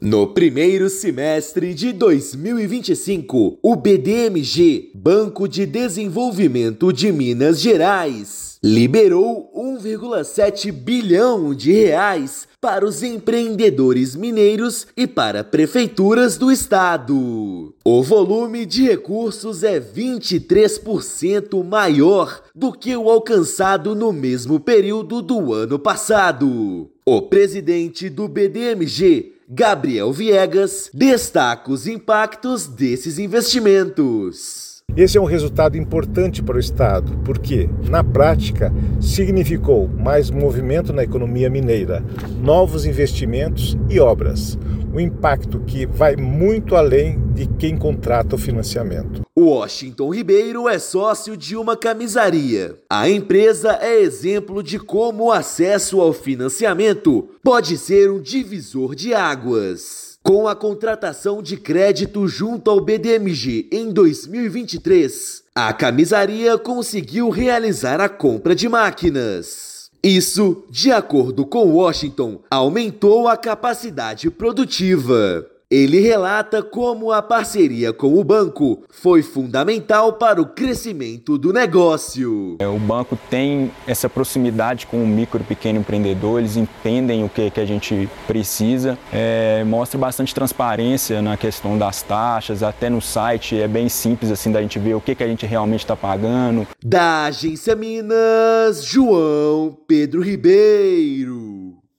[RÁDIO] Com empresas mineiras investindo mais, BDMG bate novo recorde em 2025 e alcança R$ 1,77 bilhão em financiamentos
Neste primeiro semestre, banco reduziu taxas, lançou novos produtos e contribuiu para estimular 45 mil empregos em Minas. Ouça matéria de rádio.